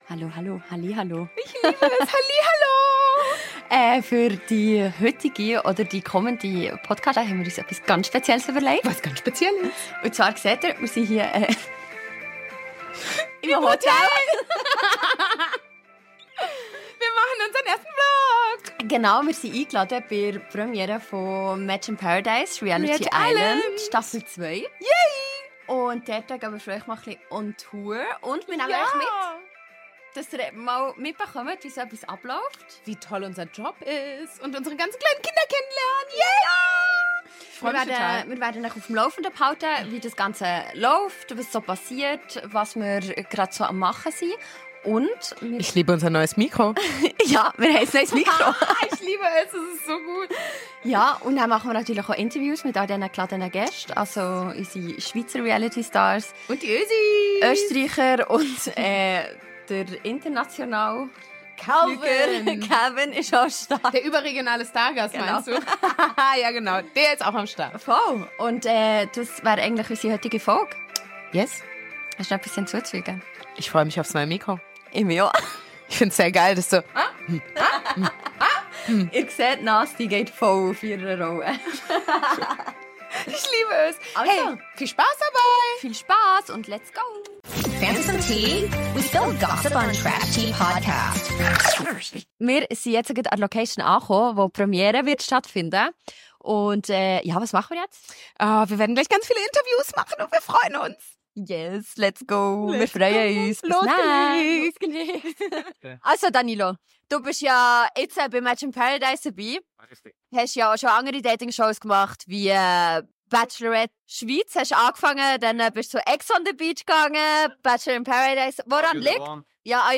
Heute gibt es keine normale Podcast-Folge, denn wir sind an die Premiere von Reality Island / Match in Paradise eingeladen worden. Dabei wollen wir euch natürlich teilhaben lassen & haben deshalb diese Folge als "Vlog" zusammengeschnitten, so dass ihr fast live dabei seid.